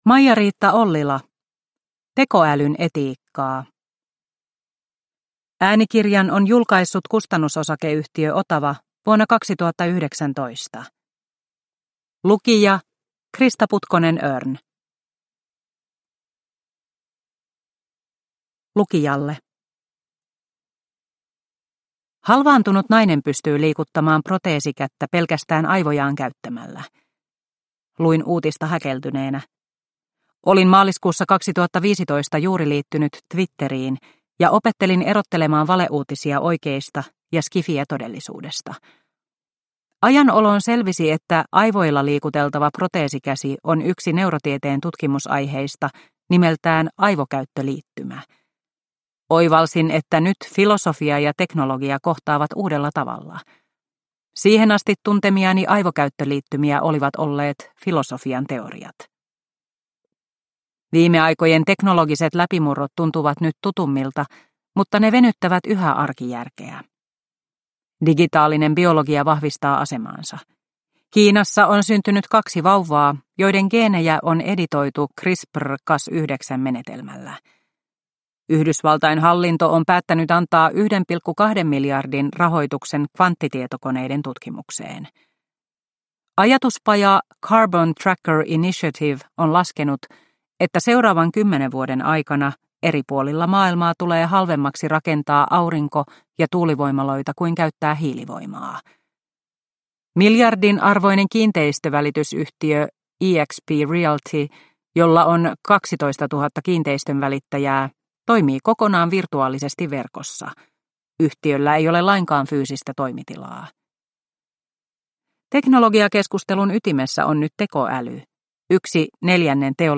Tekoälyn etiikkaa – Ljudbok – Laddas ner